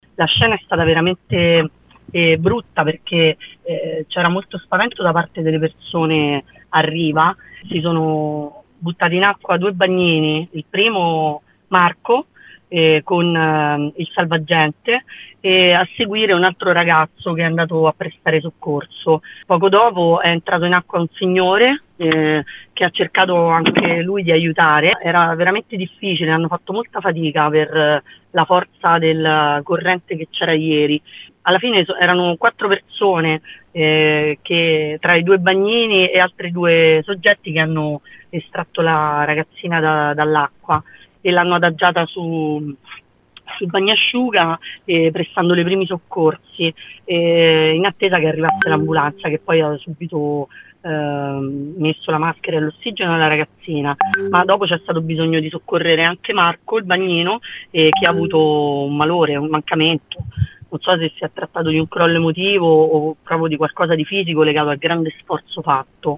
Il racconto di una testimone